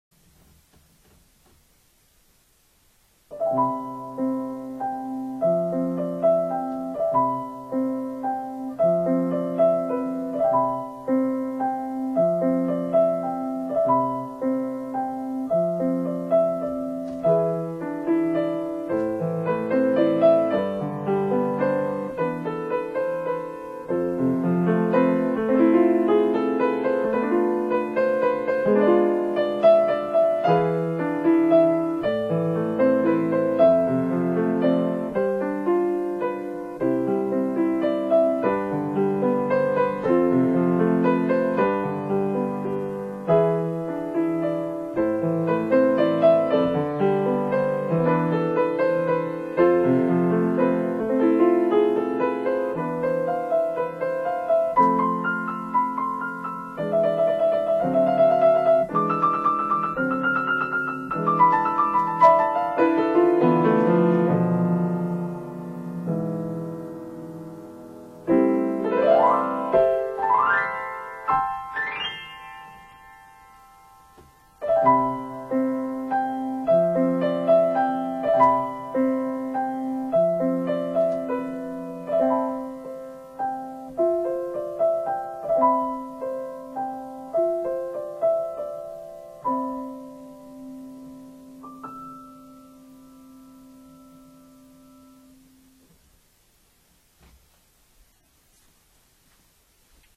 2004年4月に訪れたフランスの世界遺産モンサンミッシェルの思い出をピアノ曲にしました。
2004.6.4 高槻ブロードキャスト収録の為録音